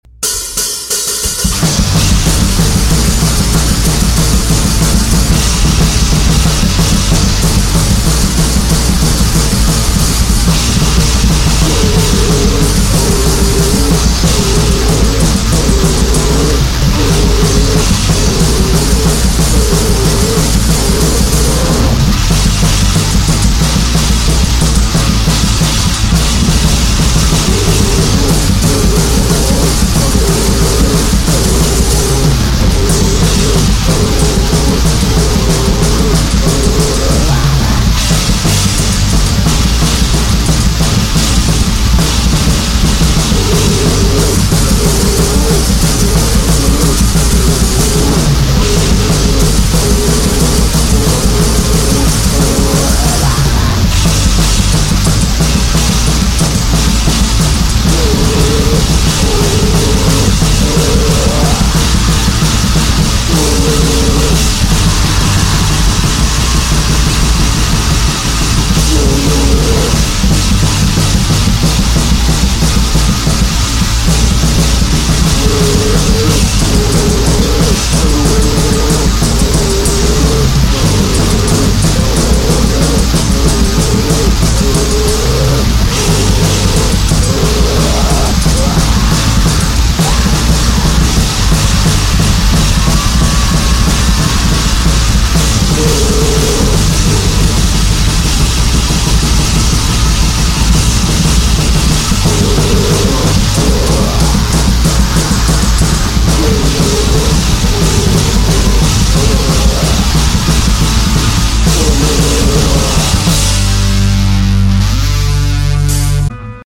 EstiloHardcore